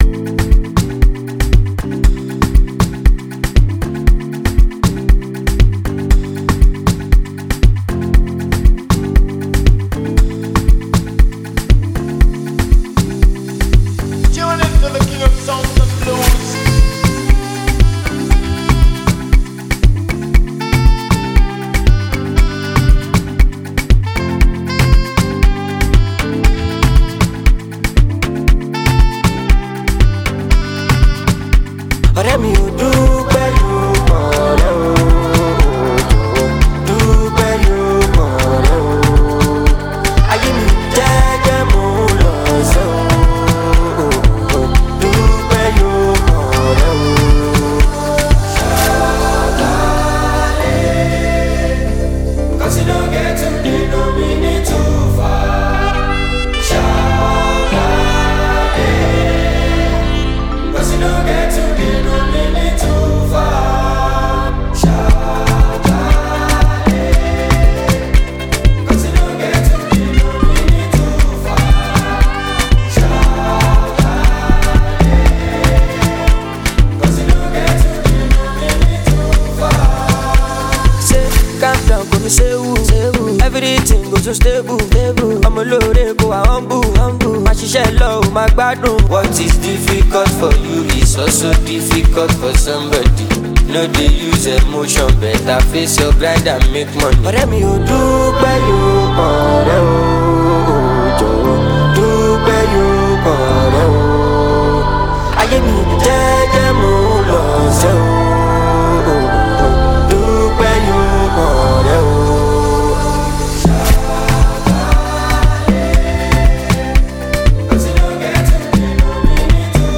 smooth vocals and the catchy hook
blends Afrobeat with contemporary sounds
With its upbeat tempo and catchy sounds